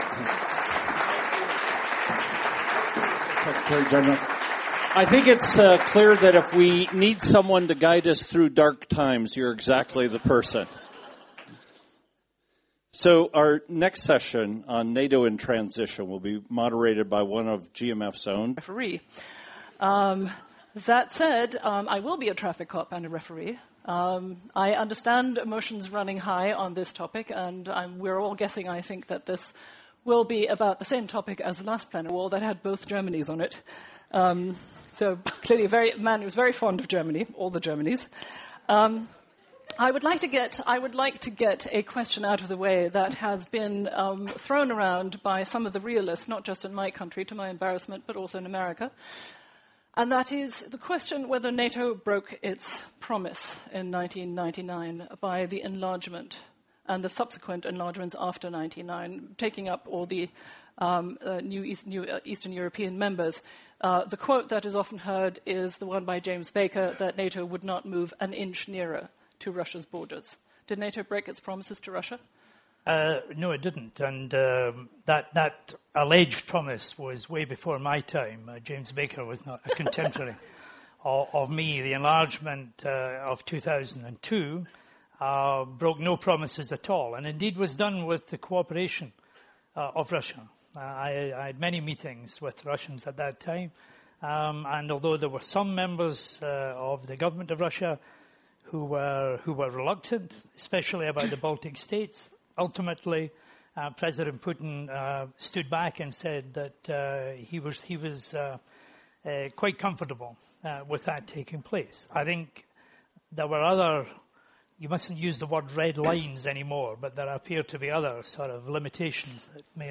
Speech by NATO Secretary General Anders Fogh Rasmussen at the ''Brussels Forum''